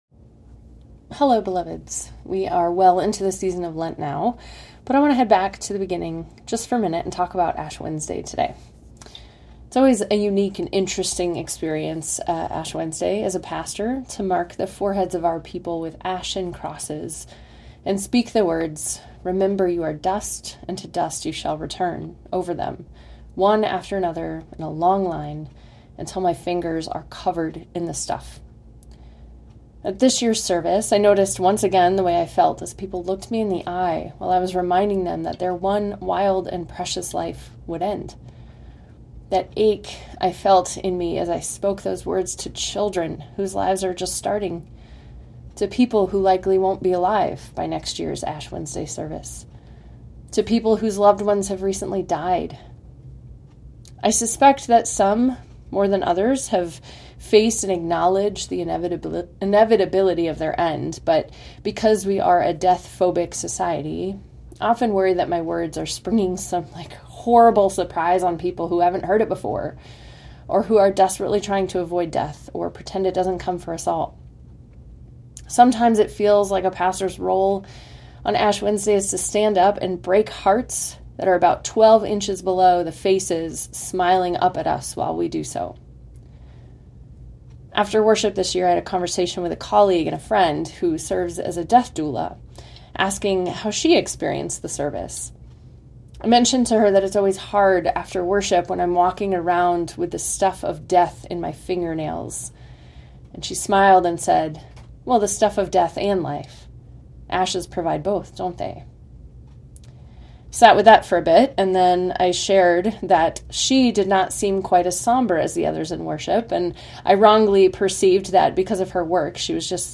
"Grow Spiritually" is a monthly guided devotional, offered for use with your family or on your own. March's theme is Ashes.